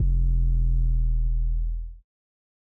Metro 808s [Plugg].wav